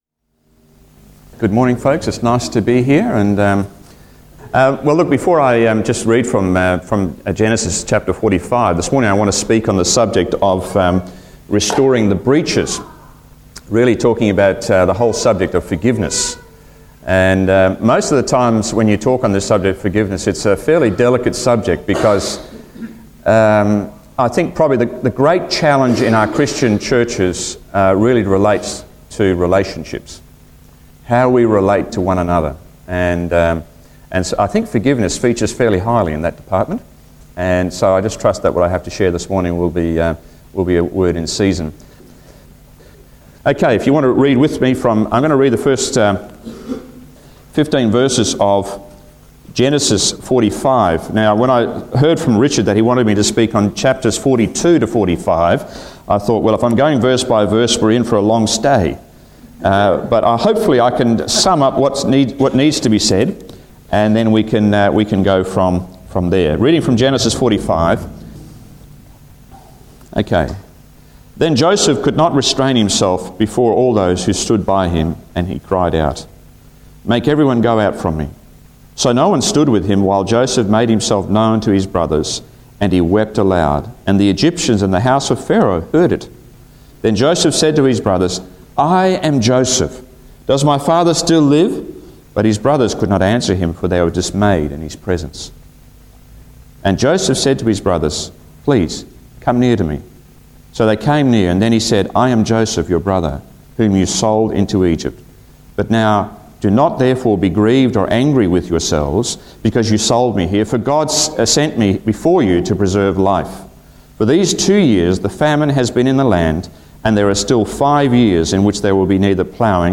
Genesis 42-45 Service Type: Family Service Topics: Forgiveness , Joseph's Brothers « Joseph